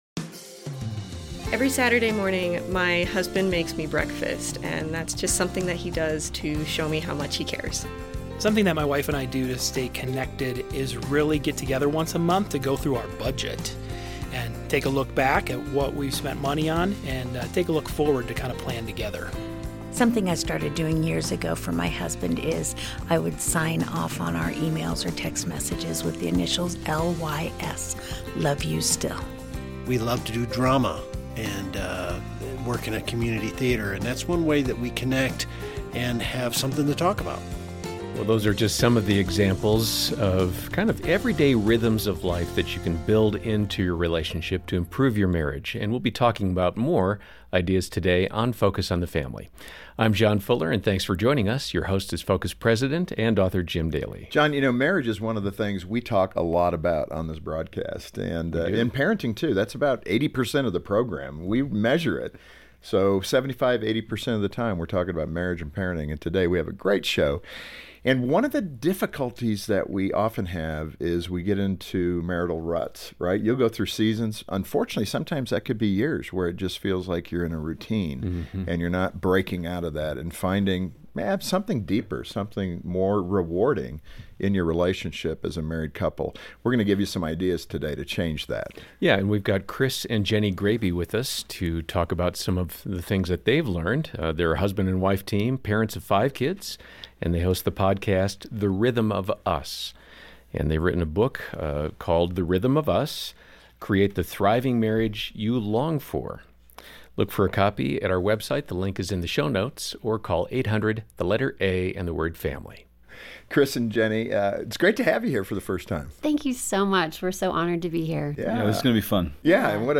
In this interview, they'll focus on the important rhythms that have helped them through the years, and how God uses those rhythms to draw us closer to Him.